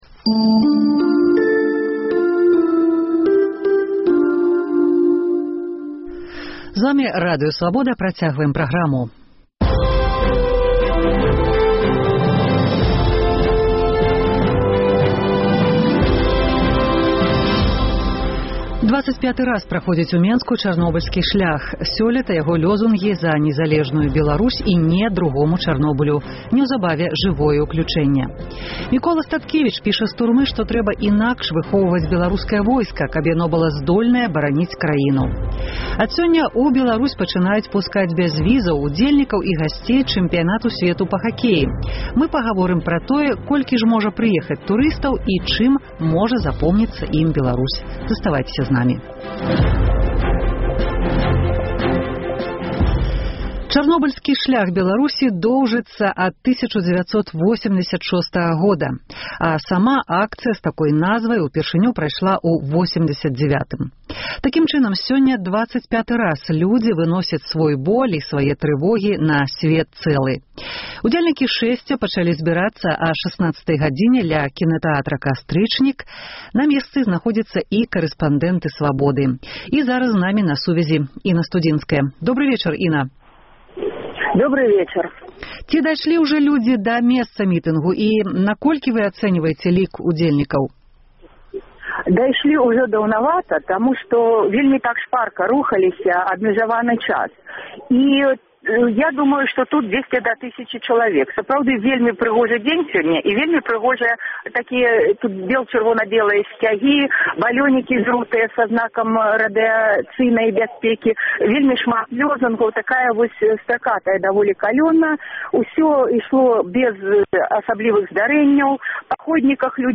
Жывое ўключэньне напачатку кожнай гадзіны, адразу пасьля міжнародных навінаў.
Рэпартаж з Хойніцкага раёну пра тое, якая прадукцыя вырошчваецца на заражаных землях і куды яна ідзе. Чаму Чарнобыльская тэма ўжо не зьяўляецца для большасьці беларусаў значнай і актуальнай?
Адказваюць жыхары Берасьця.